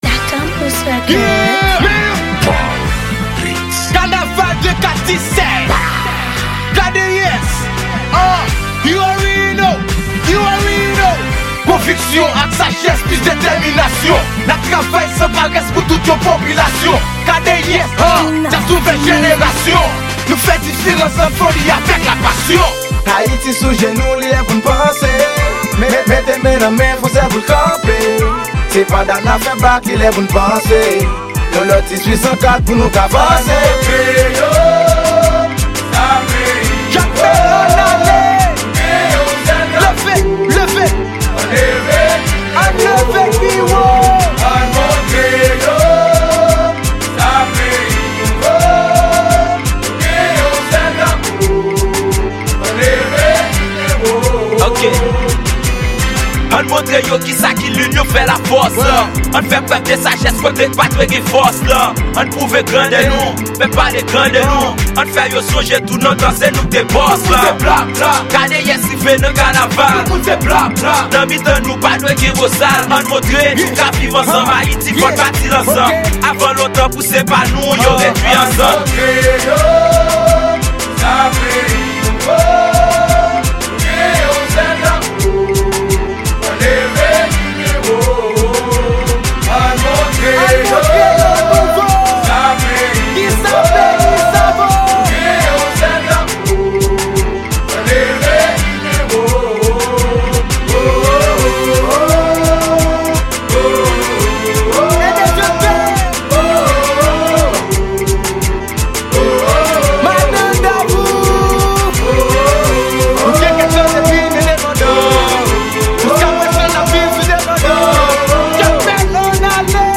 Genre: Kanaval.